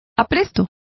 Complete with pronunciation of the translation of stiffener.